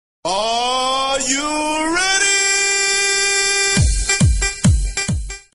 Tonos EFECTO DE SONIDO DE AMBIENTE de ARE YOU READY VIDEO